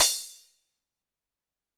Drums_K4(22).wav